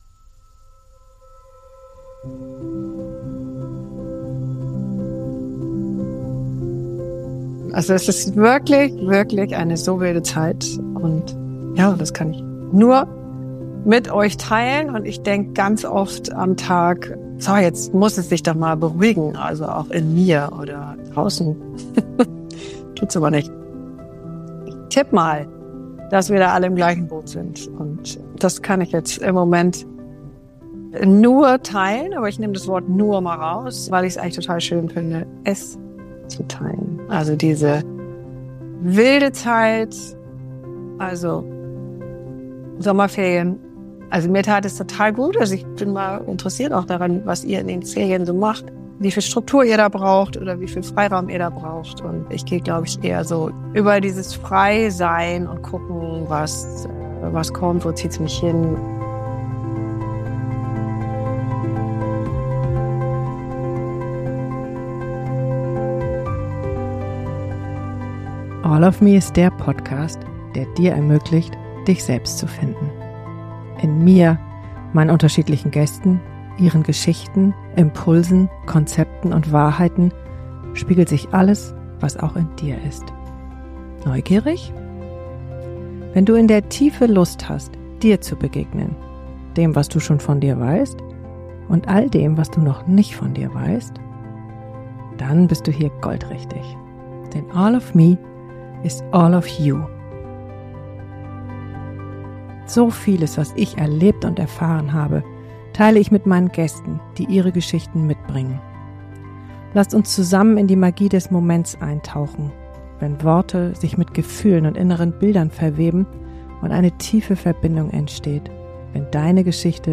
In der ersten Solo-Folge nach der Sommerpause reflektiere ich über die Bedeutung von Sommerferien und die Gefühle, die da drinstecken.